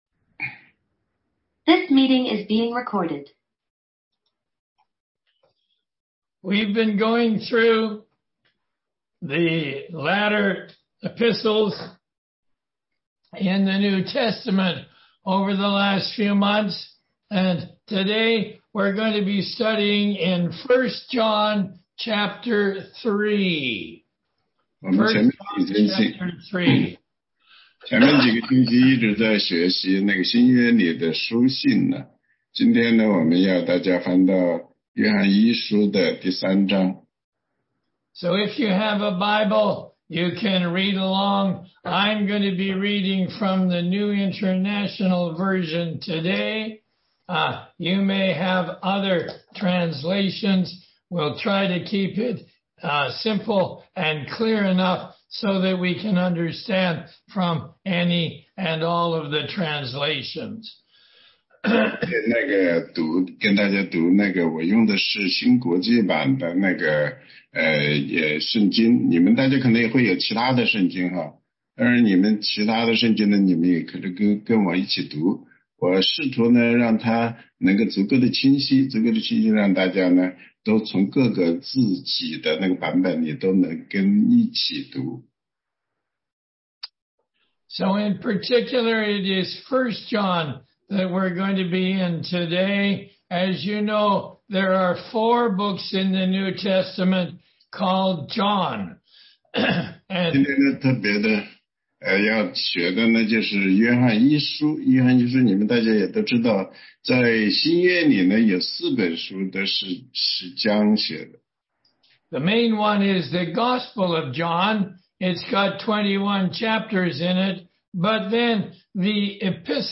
16街讲道录音 - 约一3章
答疑课程